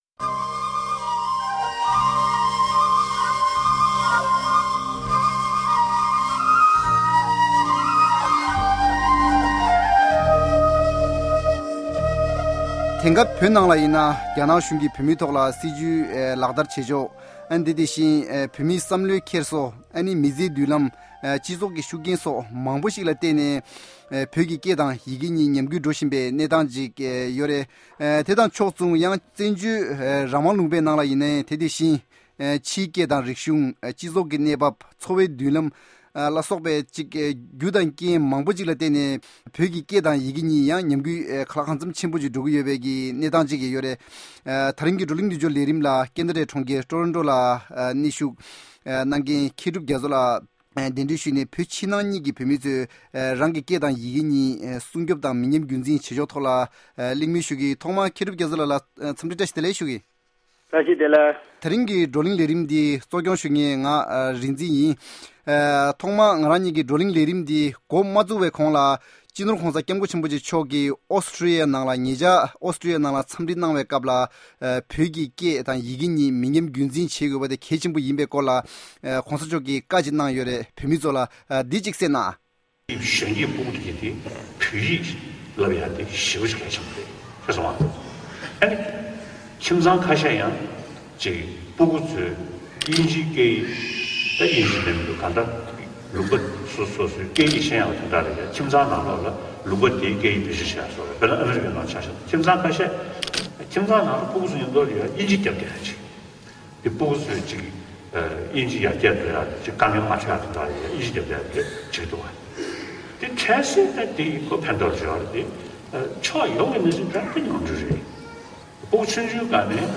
In exile, there is ever more discussions on preservation of Tibetan language, which forms the bedrock of Tibetan identity, civilization and way of life. Table Talk discusses the way and means of preserving and promoting Tibetan language inside and outside Tibet.